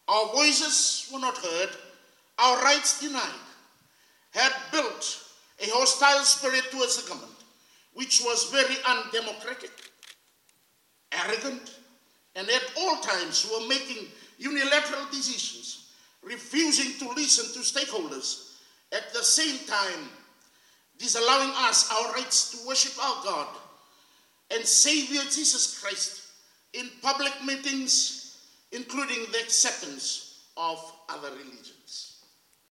made the comments while opening the 89th Annual Delegates Conference of the Association in Suva this morning.